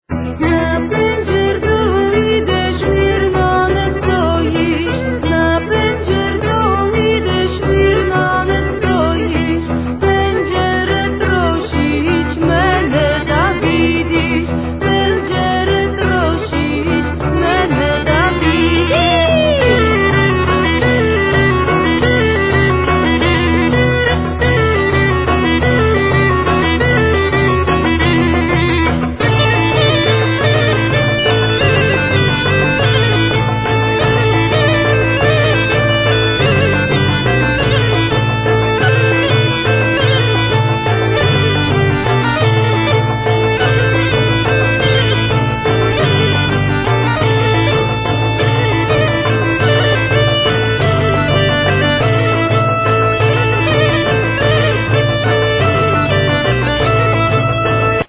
Tampoura, Gajda, Percussions, Chour, Vocal
Violin, Darbuka, Percussions, Choir, Vocal
5 strings fiddle, Percussions, Choir. Vocal
Davul, Darbuka, Pandeireta, Choir, Vocal
Double bass, Contras, Choir